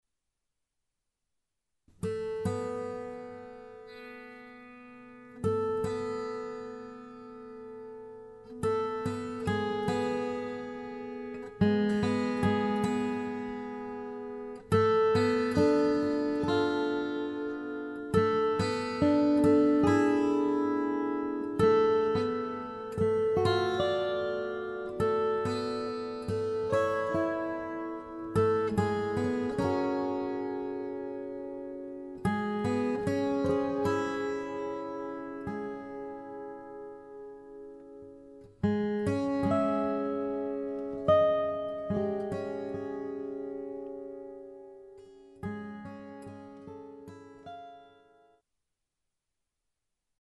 for 6-string and 12-string guitar duet